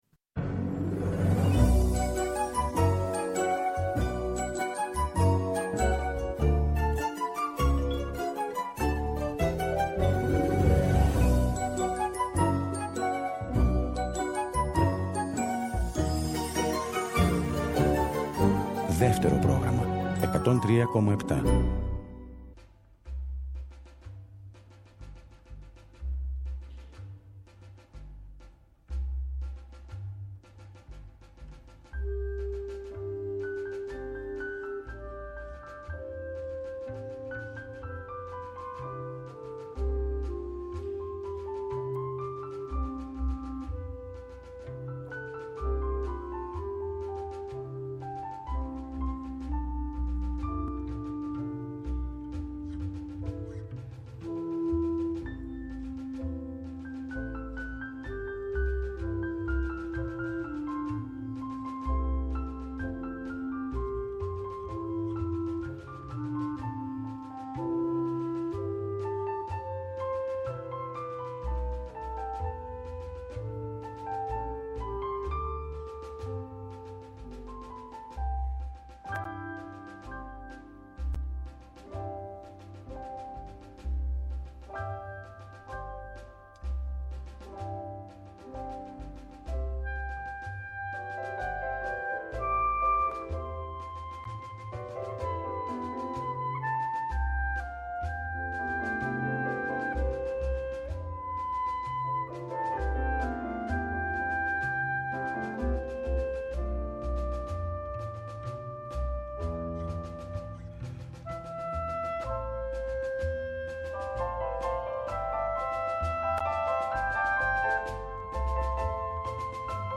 Στις Νυχτερινές ώρες η Μουσική και τα Τραγούδια πολύτιμη συντροφιά.